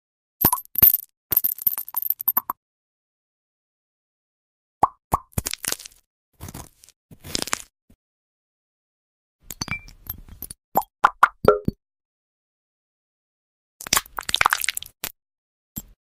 Spreading Mini Glass Fruits on sound effects free download
Spreading Mini Glass Fruits on a Toast and Pancake ASMR!